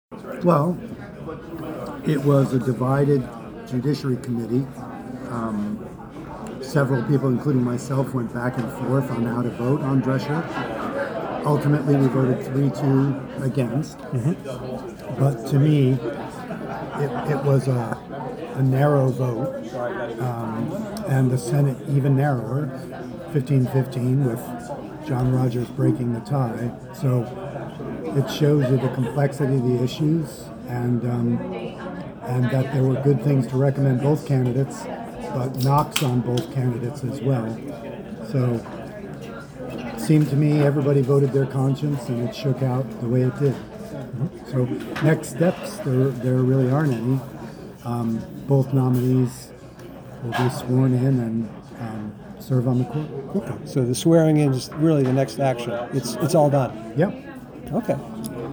Senate Pro Tem Phil Baruth says the vote was evenly split